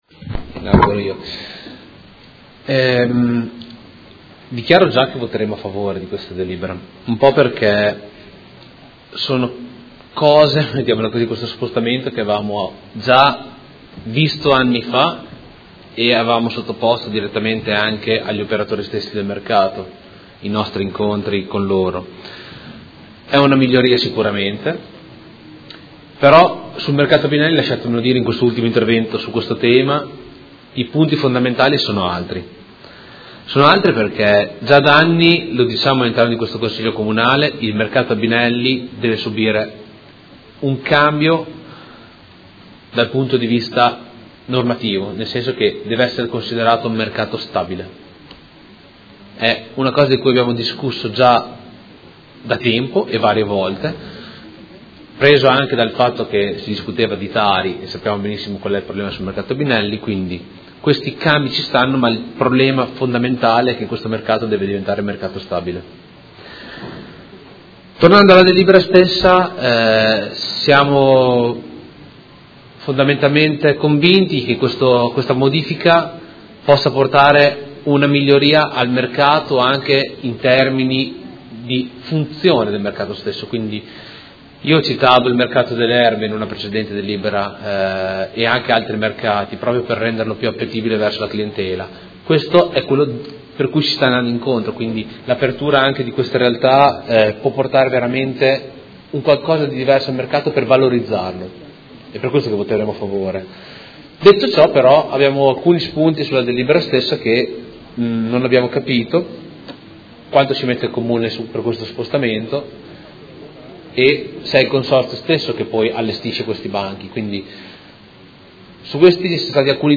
Luca Fantoni — Sito Audio Consiglio Comunale
Seduta del 28/03/2019. Dichiarazione di voto su proposta di deliberazione: Regolamento comunale del Mercato quotidiano di generi alimentari denominato “Mercato Albinelli” – Approvazione